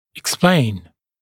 [ɪk’spleɪn] [ek-][ик’сплэйн] [эк-]объяснять